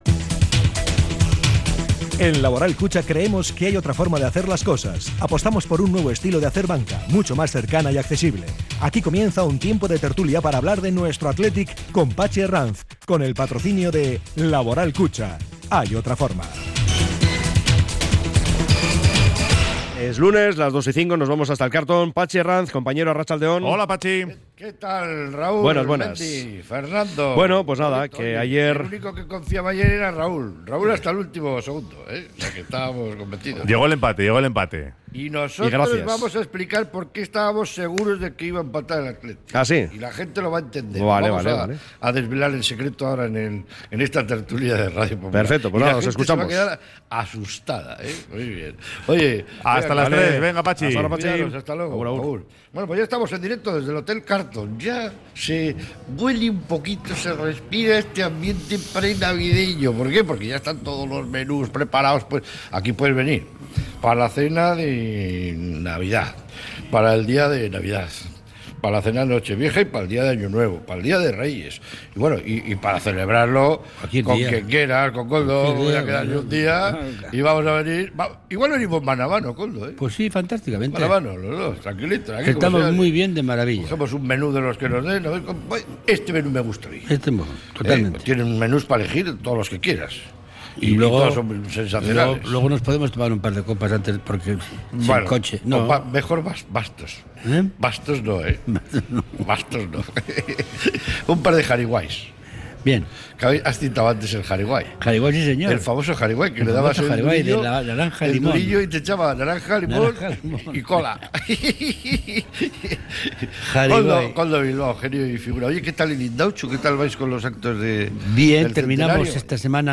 desde el Hotel Cartlton